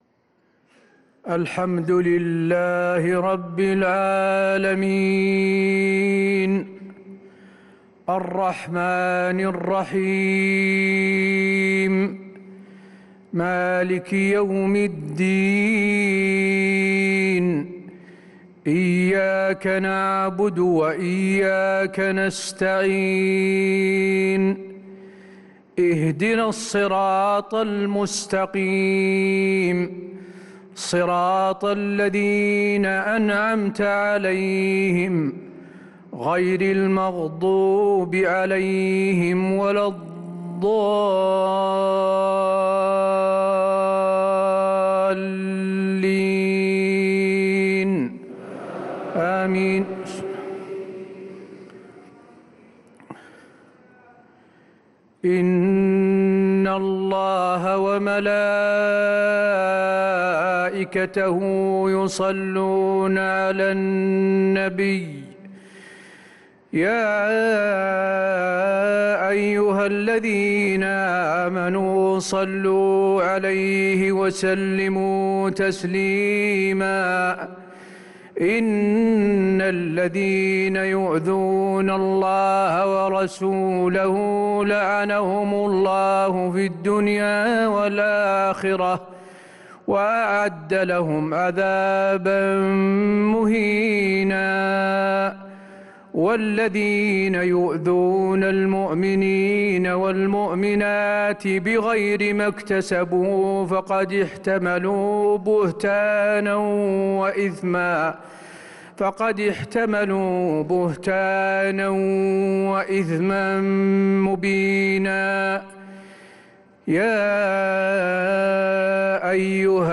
صلاة العشاء للقارئ حسين آل الشيخ 26 رمضان 1445 هـ